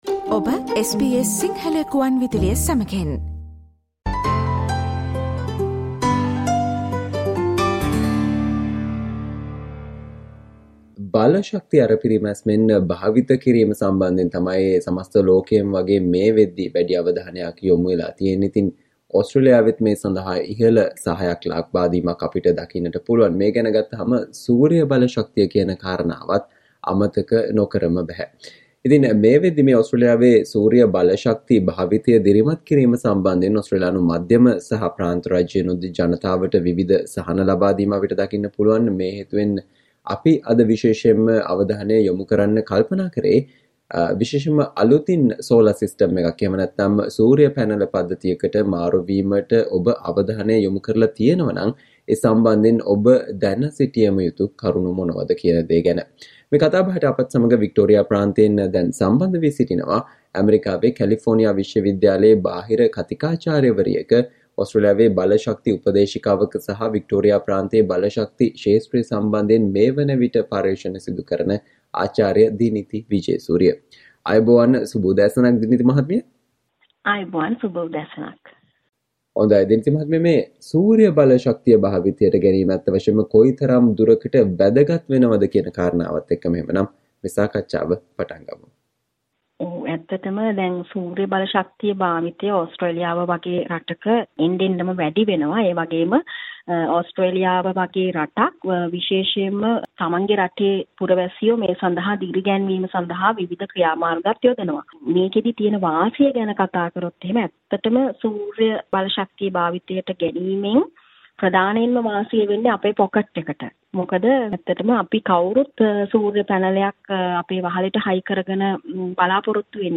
Listen to the SBS Sinhala discussion on Things you should pay attention to if you intend to switch to a new "Solar system"